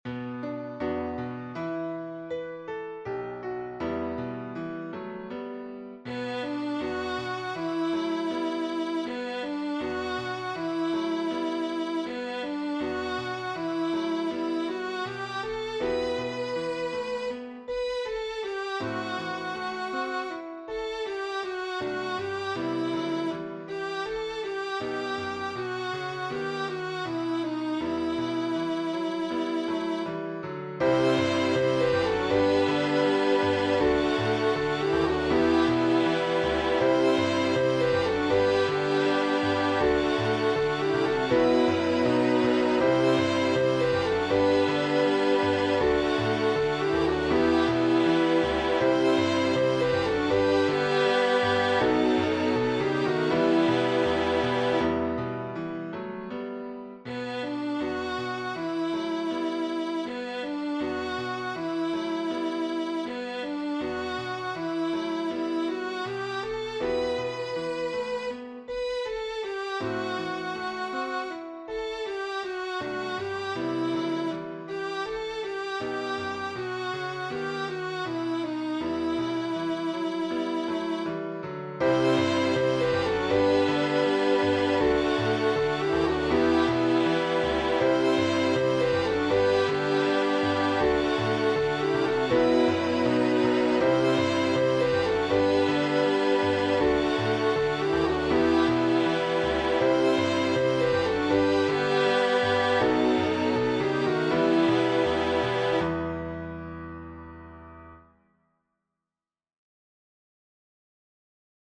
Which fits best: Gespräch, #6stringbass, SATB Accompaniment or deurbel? SATB Accompaniment